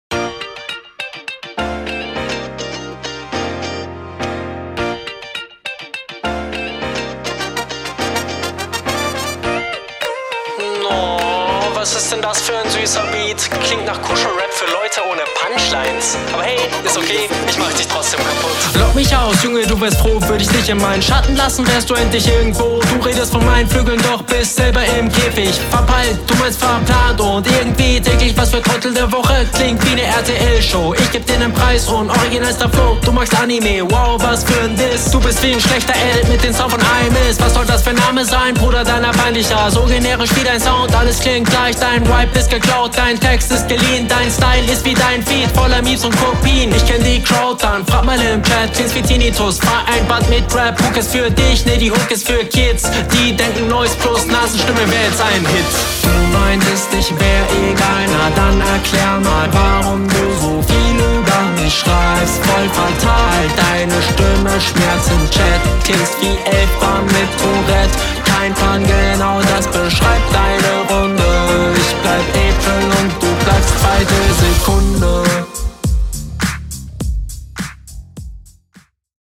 Der Flow ist ein wenig abgehackt, das wirkt ein bisschen stockend, ansonsten geht der Flow …
Fand die Konter nicht so stark und Flow war auch bisschen unflüssig.